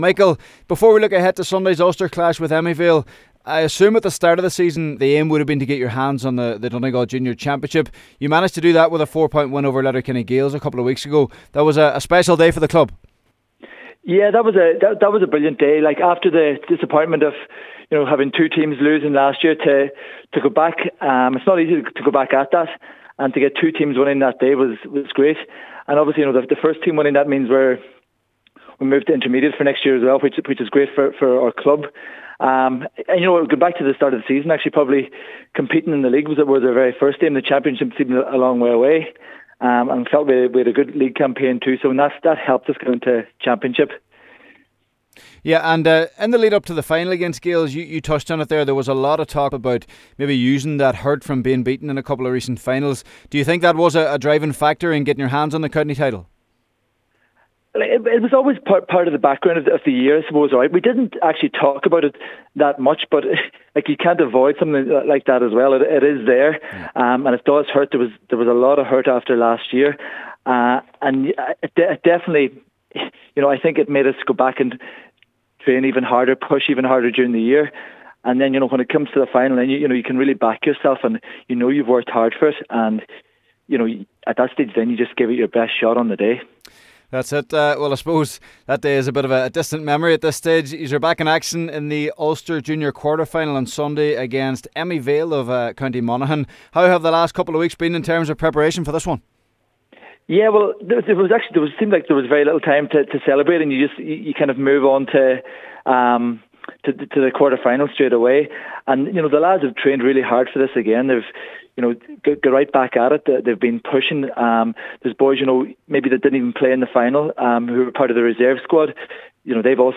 Speaking to Highland’s